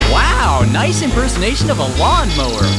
Play Wow Lawnmower RC STUNT COPTER - SoundBoardGuy
Play, download and share Wow Lawnmower RC STUNT COPTER original sound button!!!!
wow-lawnmower.mp3